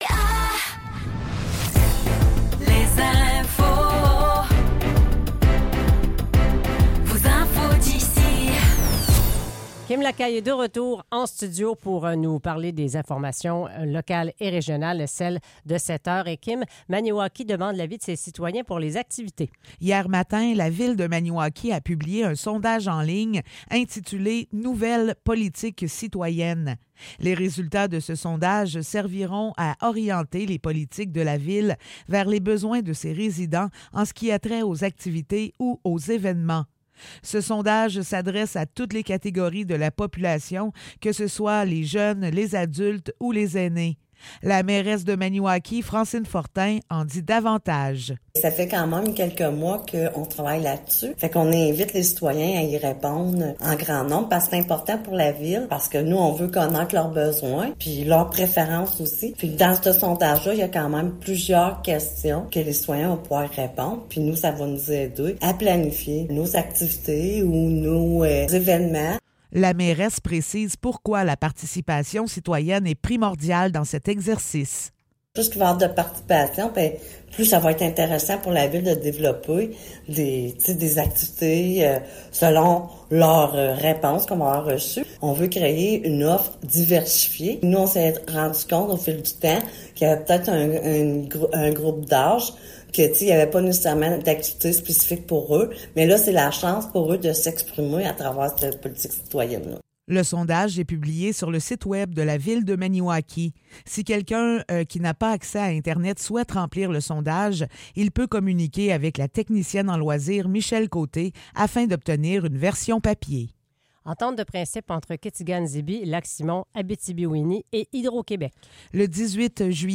Nouvelles locales - 26 juillet 2024 - 7 h